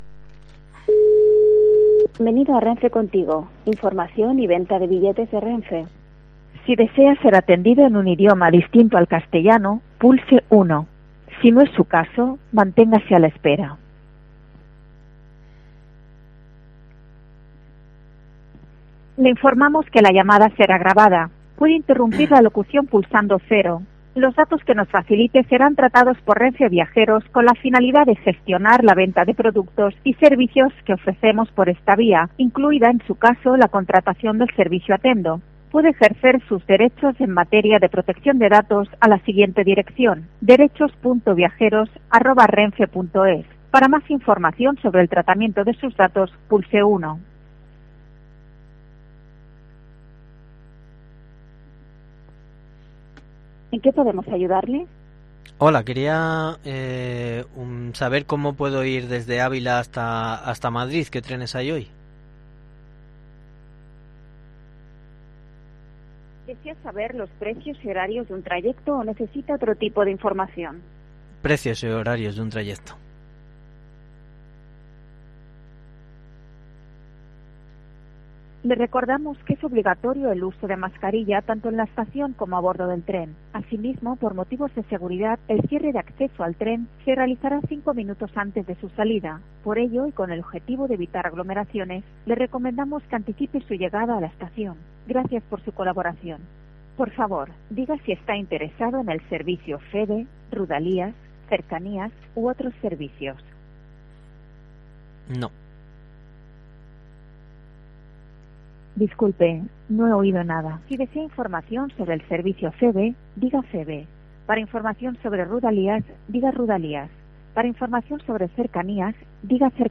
La surrealista e infructuosa llamada para conseguir un billete de tren entre Ávila y Madrid